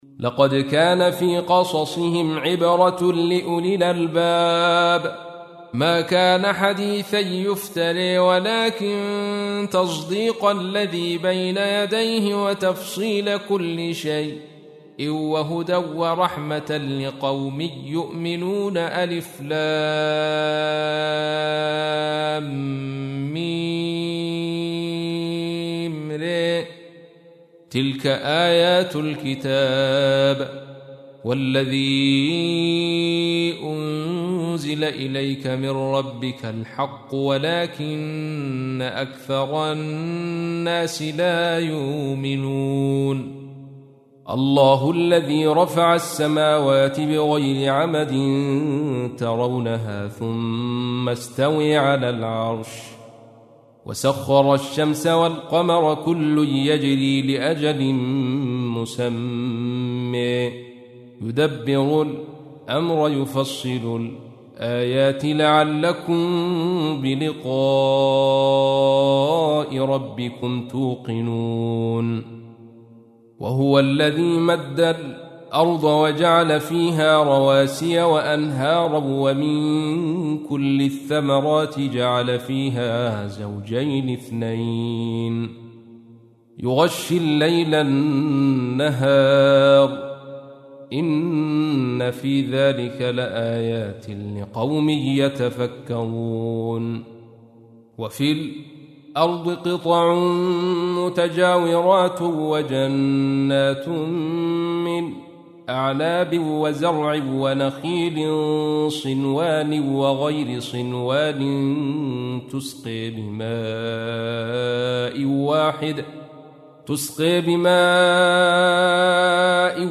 تحميل : 13. سورة الرعد / القارئ عبد الرشيد صوفي / القرآن الكريم / موقع يا حسين